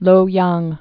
(lōyäng)